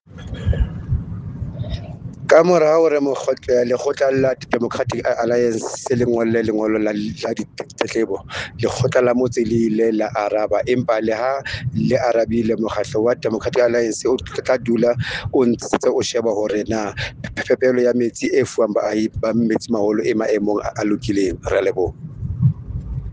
Sesotho soundbites by Cllr Thulani Mbana and